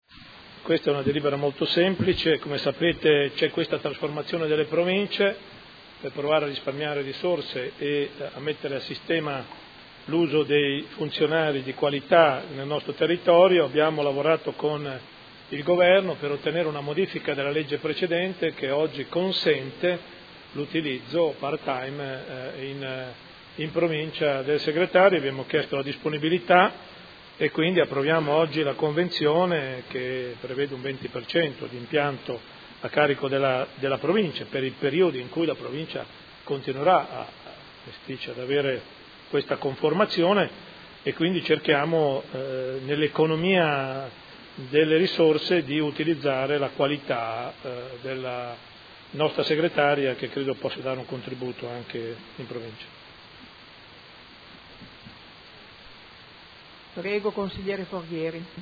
Gian Carlo Muzzarelli — Sito Audio Consiglio Comunale
Seduta del 22 ottobre. Proposta di deliberazione: Convenzione tra il Comune di Modena e la Provincia di Modena per il servizio in forma associata delle funzioni di Segretario